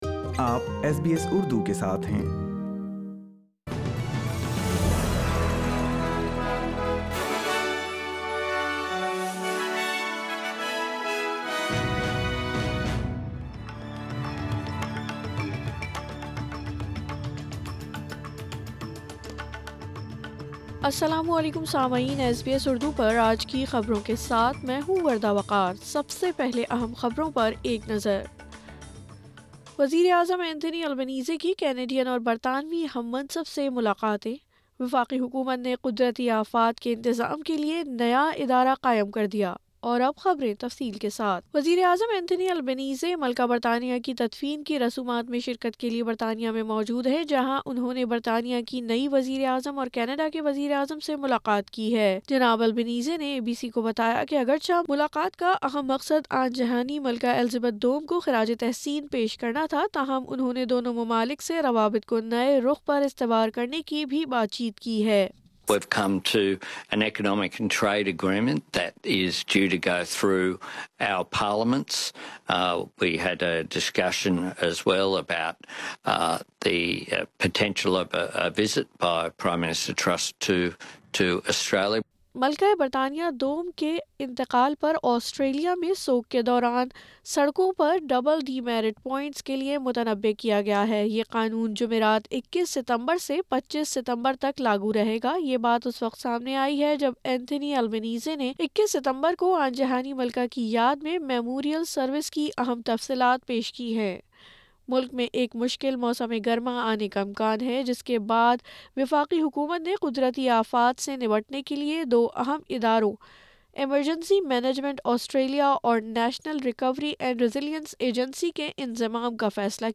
Urdu News Monday 19 September 2022